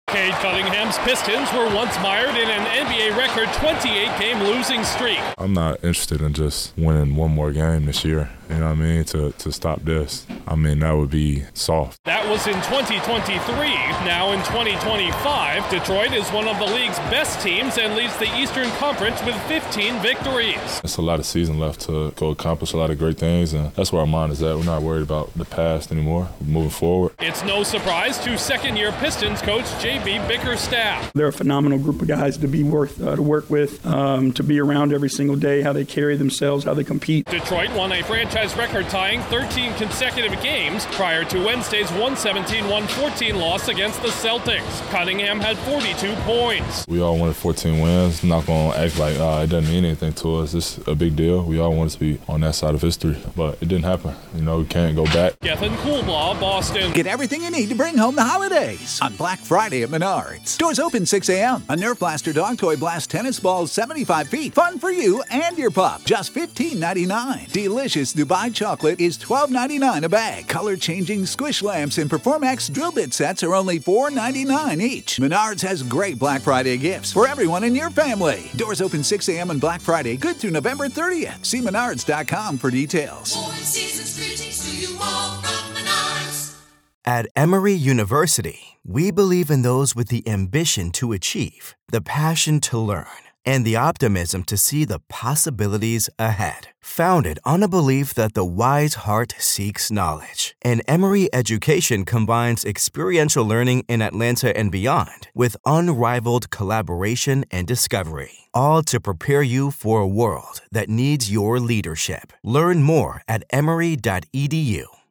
Detroit basketball is back after getting off to one of the hottest starts in the NBA. Correspondent